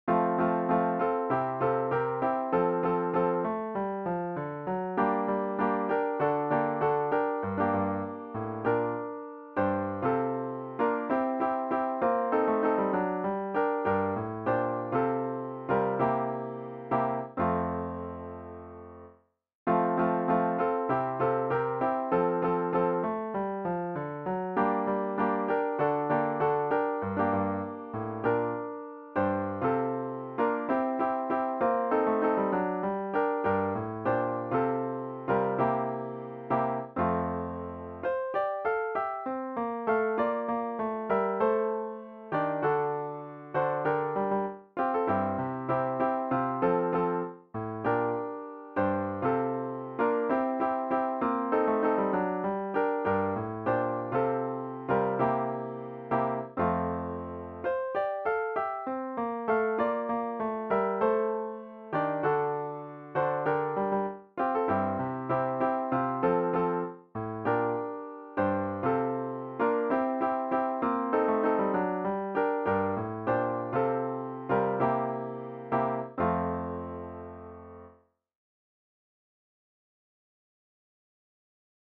Key signature: F major